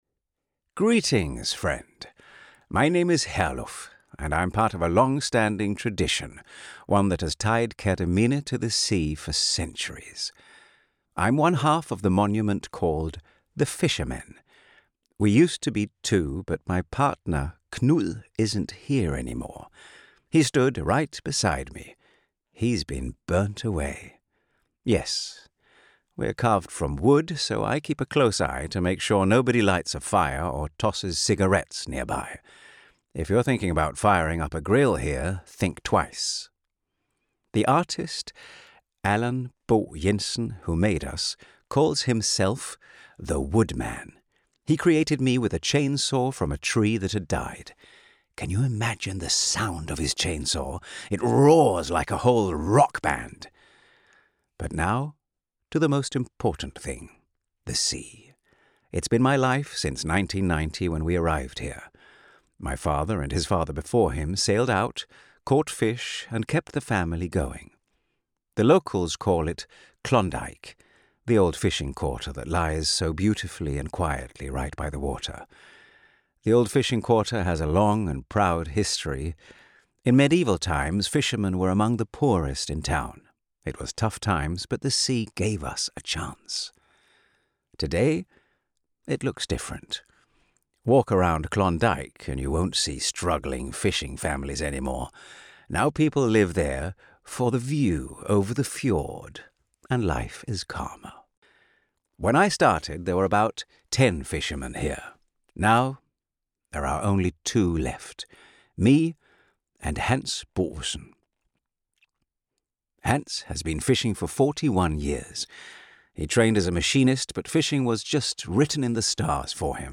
The monologue lasts just a few minutes — perfect for a quick, engaging moment during your visit.”Fiskerne” not only stands as a tribute to the past but now invites you to listen and connect with the spirit of Kerteminde’s maritime heritage in a fresh, personal way.